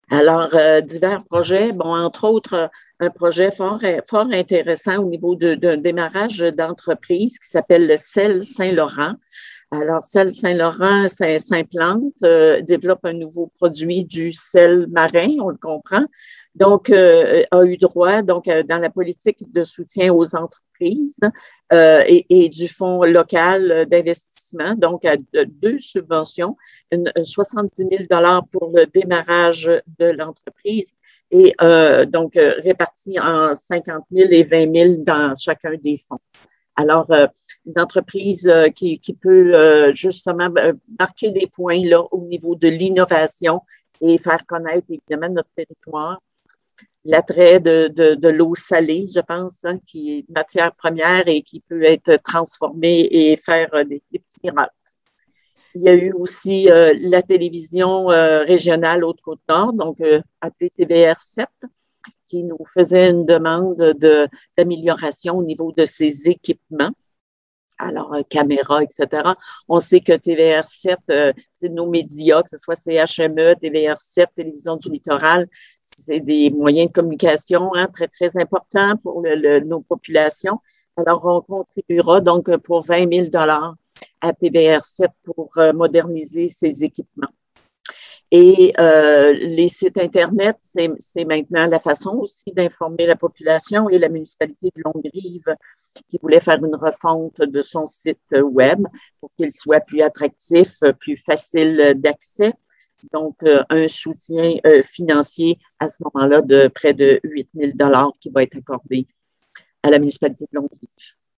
La préfet de la MRC et mairesse de Forestville, madame Micheline Anctil, a acceptée de nous faire un résumé de la séance en détaillant les points importants de la rencontre.